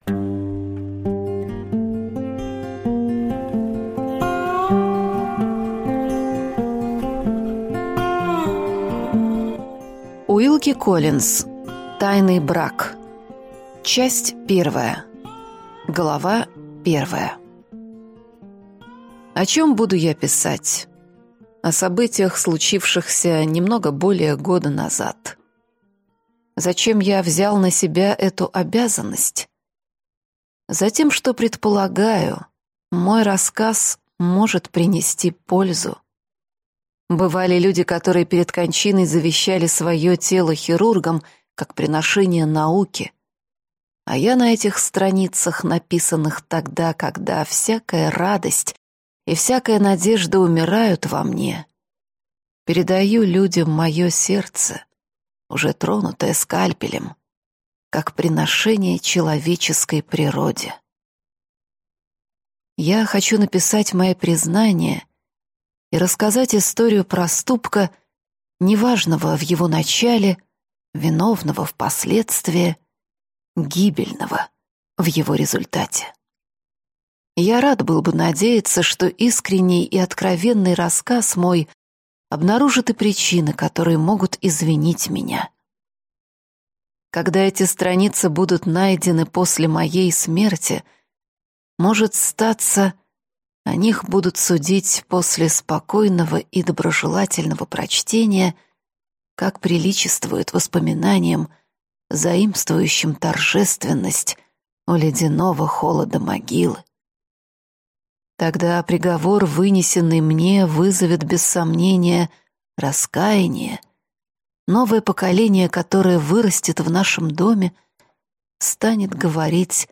Аудиокнига Тайный брак | Библиотека аудиокниг